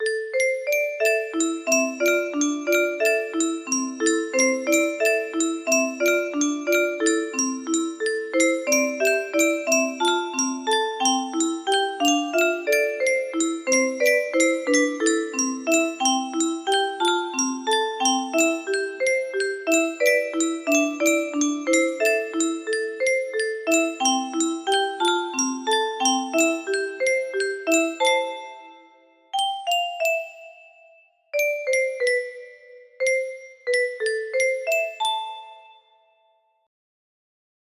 Bellente EuTom music box melody